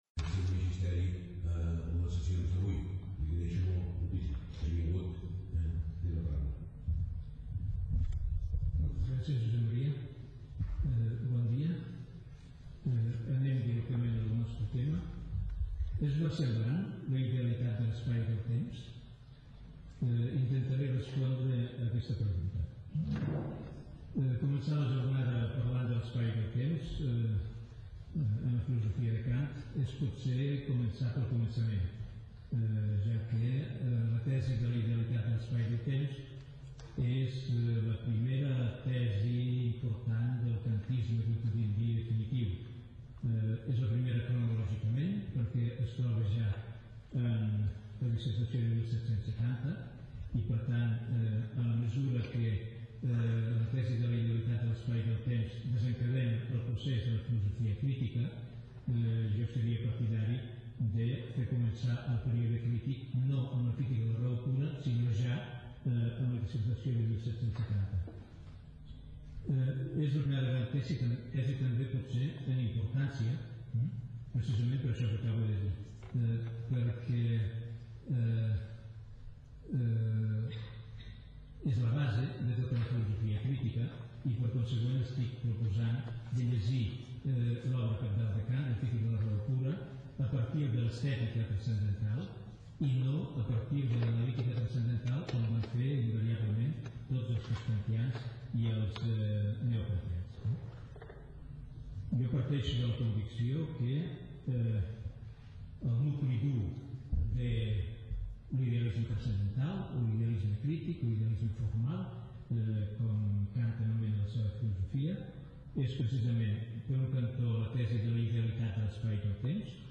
Ponència
en el marc del Simposi en motiu del 200 aniversari de la mort d'Immanuel Kant, dedicat a debatre sobre l'actualitat i presència del pensament kantià en el panorama filosòfic actual